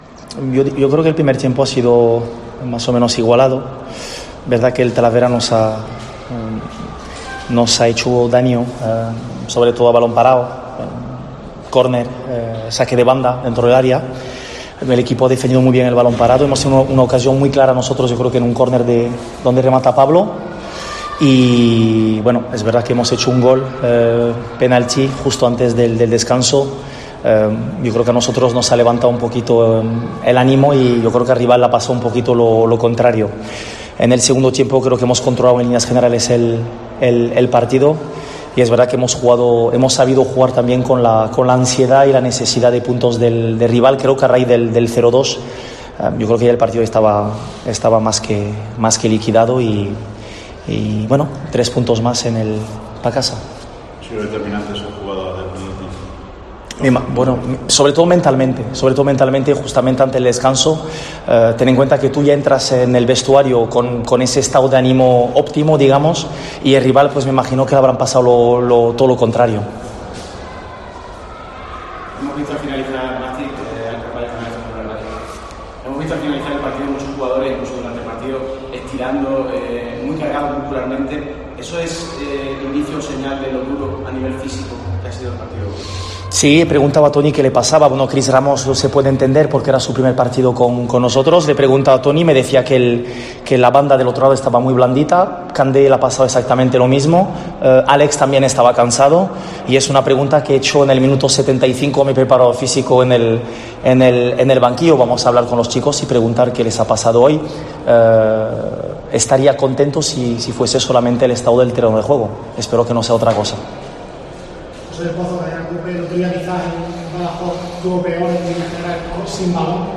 En la Rueda de prensa tras el partido